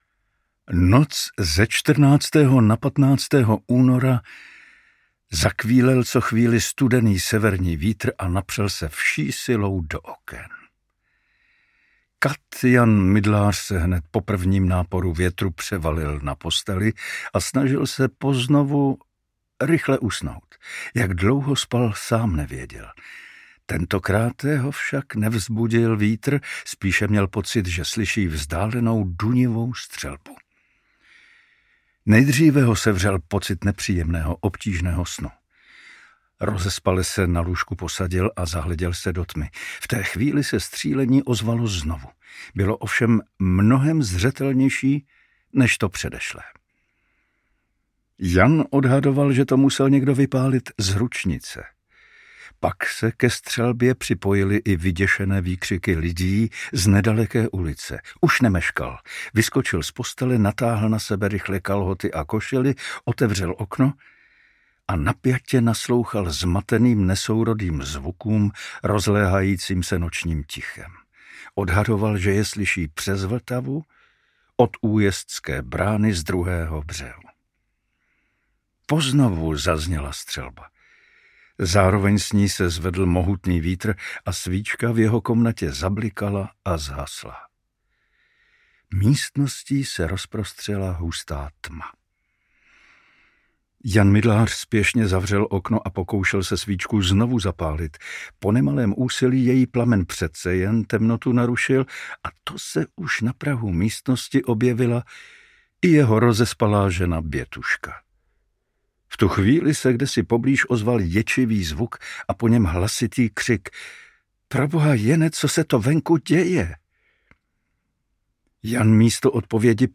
Kati ve službách císařů audiokniha
Ukázka z knihy
Vyrobilo studio Soundguru.